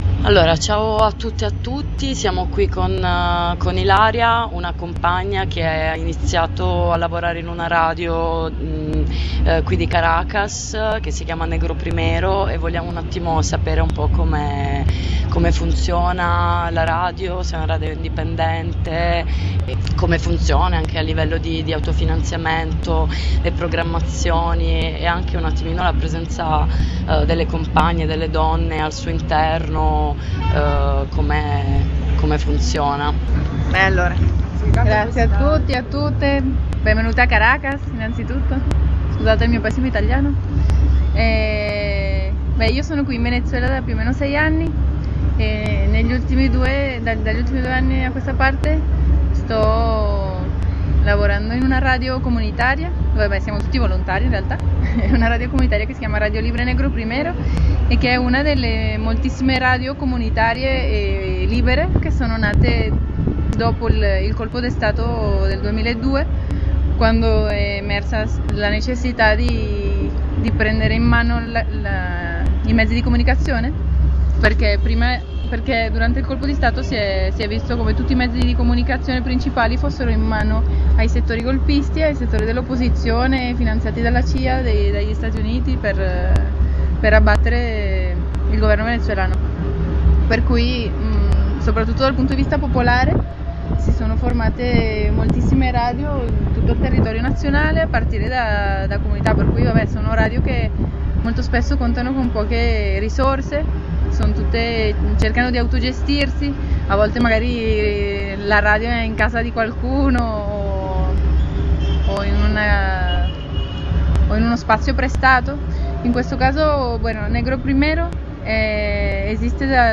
Le compagne di MeDeA hanno realizzato un’intervista